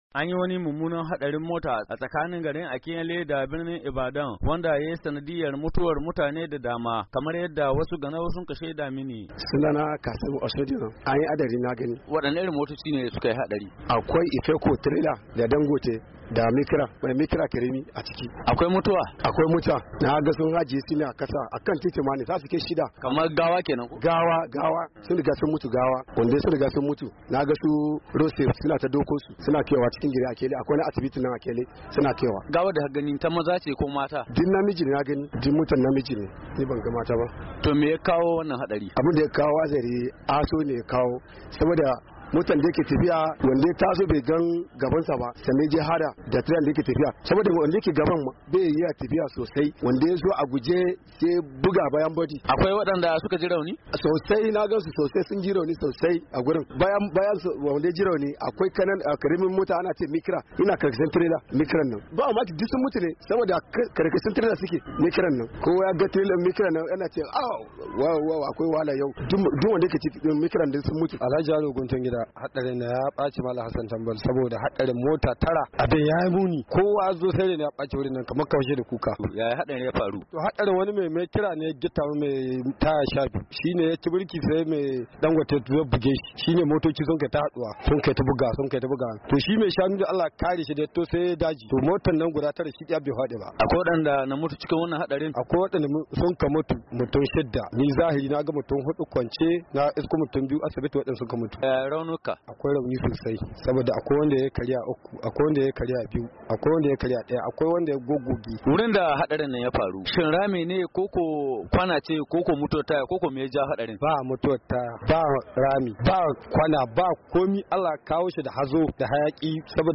Mutane da dama sun rasa rayukansu yayinda da dama suka ji raunuka a wani mummunan hadari da ya auku tsakanin garin Akinyele da Ibadan da ya shafi motoci tara. Wani ganau da Sashen Hausa ya yi hira da shi ya bayyana cewa, hadarin ya auku ne sakamakon wani mummunan hazo da ya hana direbobi ganin...